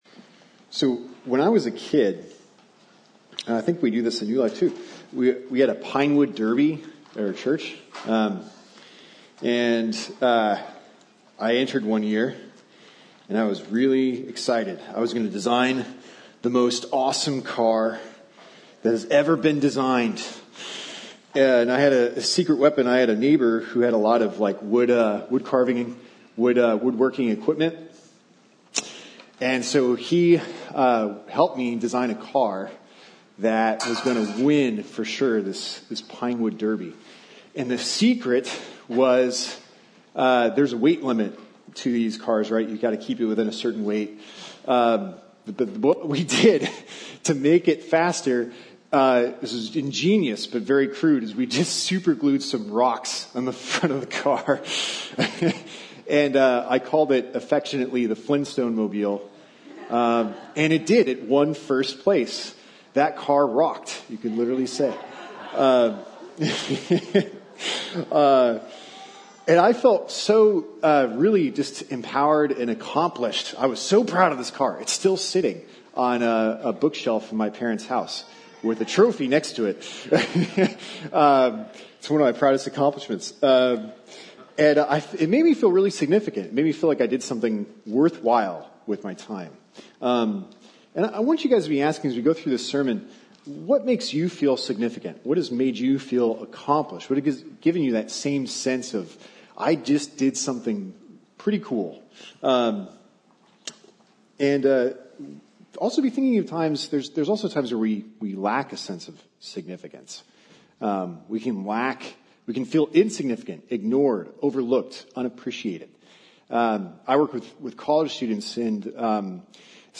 A message from the series "Earth, Wind & Fire." The Spirit was present from the Beginning of the Creation of the Earth.